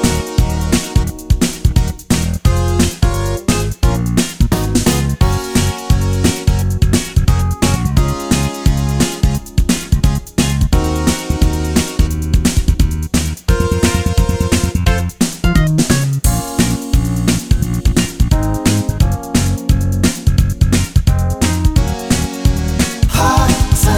no Backing Vocals Soul / Motown 3:47 Buy £1.50